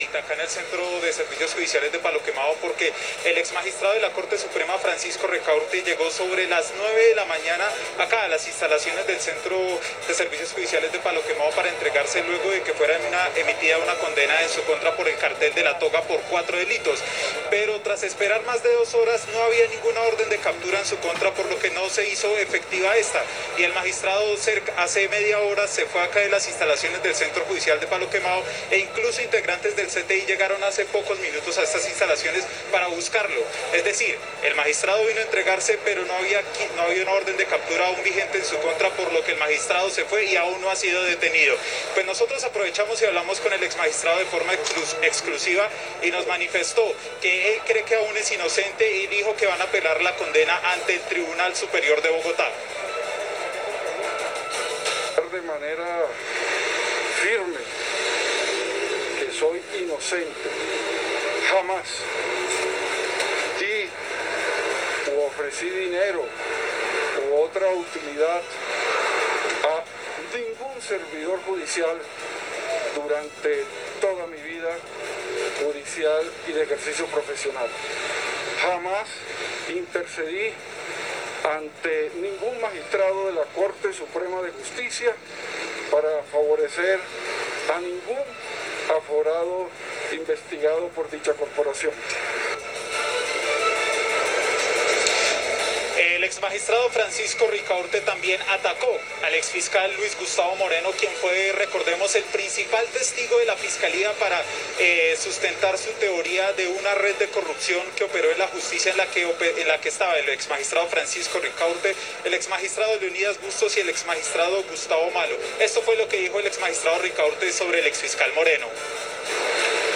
Exmagistrado Francisco Javier Ricaurte en diálogo con NoticentroUno-CM&